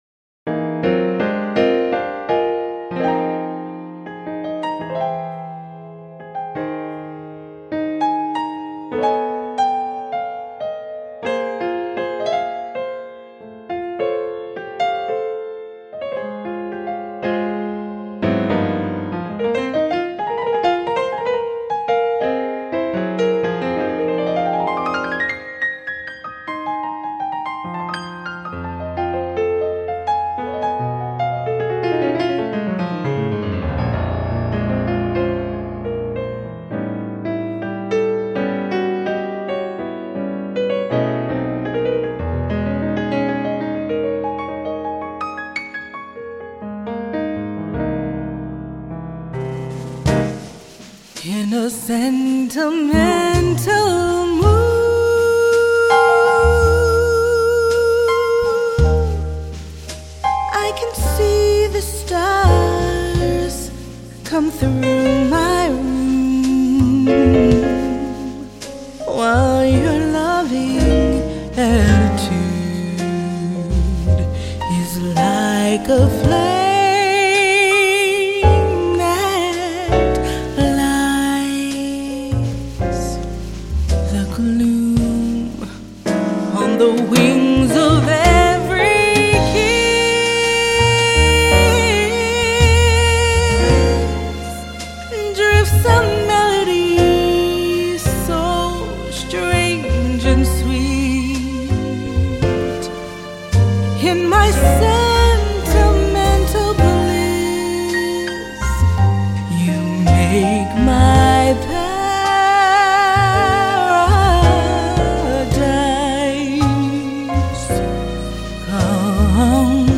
Blessed with an irresistible and soulful voice